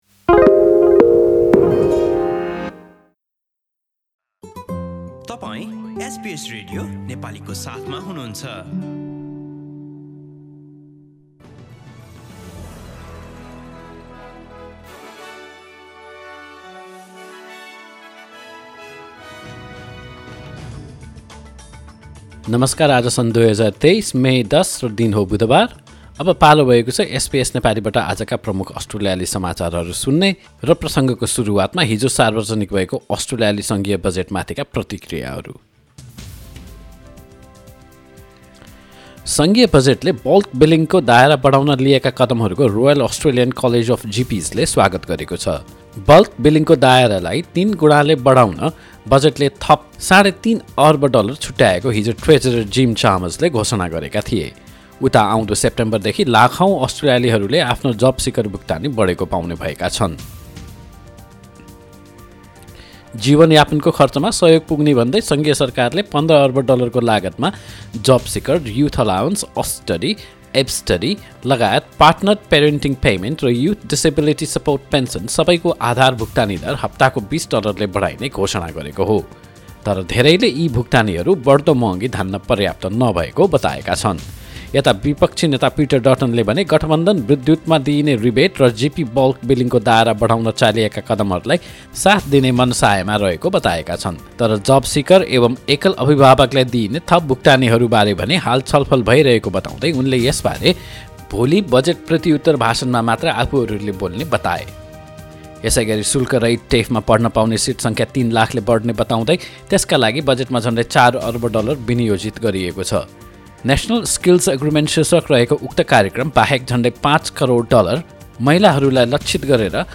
आजका मुख्य अस्ट्रेलियाली समाचार छोटकरीमा सुन्नुहोस्